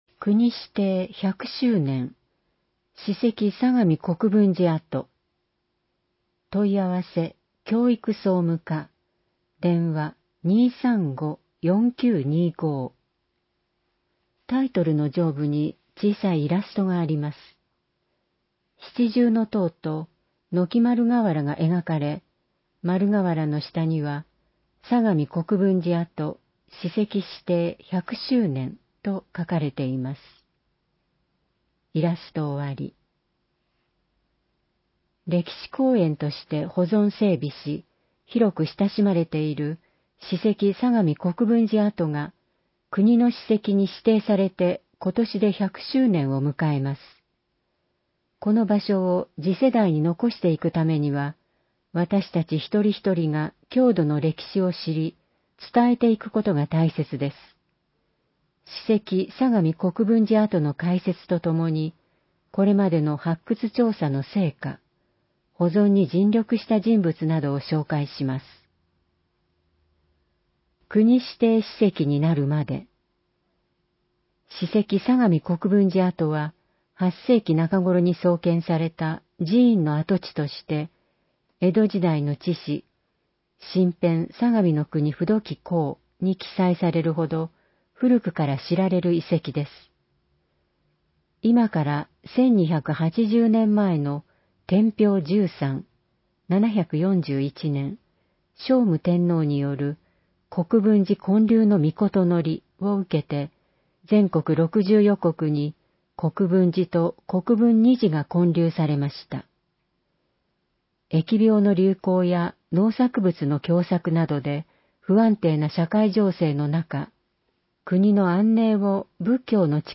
広報えびな 令和3年2月1日号（電子ブック） （外部リンク） PDF・音声版 ※音声版は、音声訳ボランティア「矢ぐるまの会」の協力により、同会が視覚障がい者の方のために作成したものを登載しています。